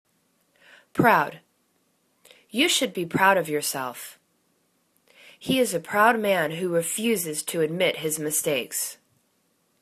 proud     /prowd/    adj